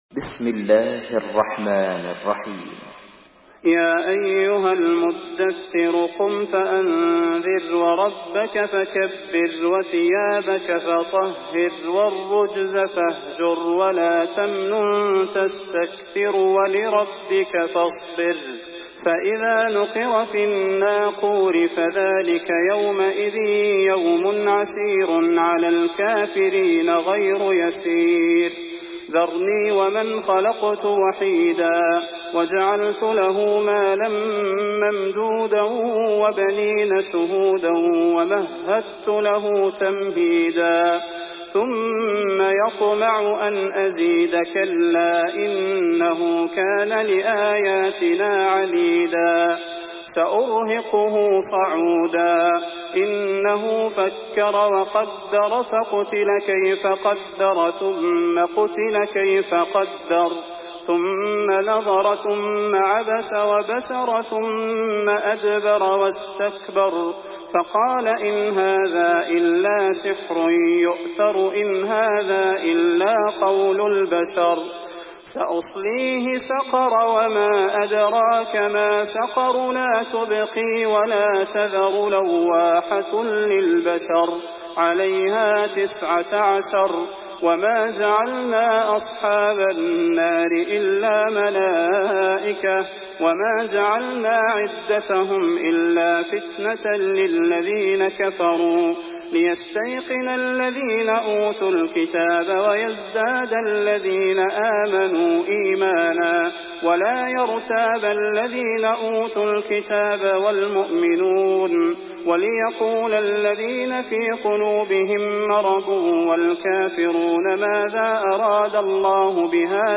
Sourate Al-Muddathir MP3 à la voix de Salah Al Budair par la narration Hafs
Une récitation touchante et belle des versets coraniques par la narration Hafs An Asim.
Murattal Hafs An Asim